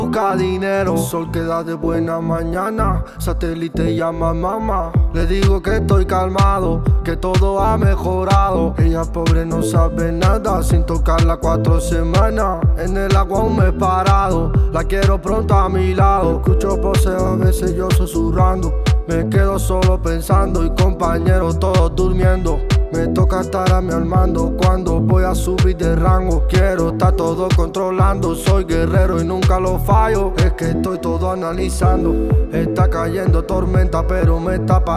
Жанр: Африканская музыка
# Afro-Beat